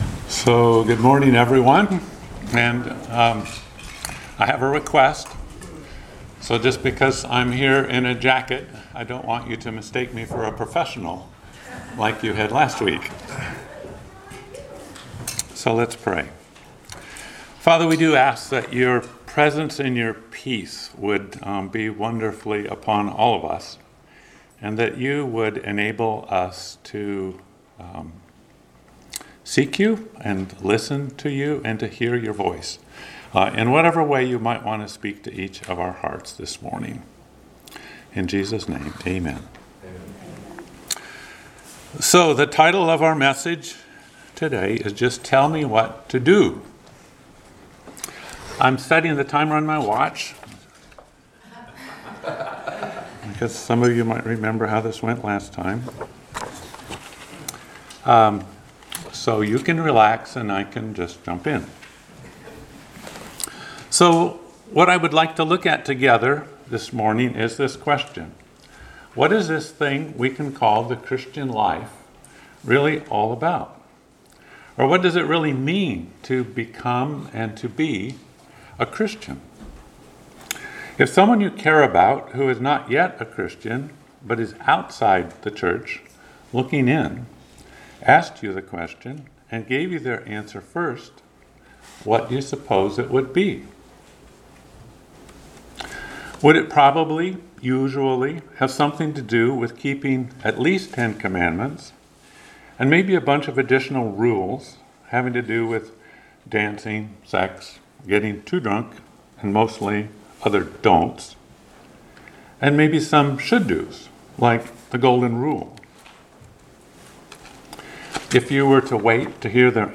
Passage: Psalm 119:33-34 Service Type: Sunday Morning Worship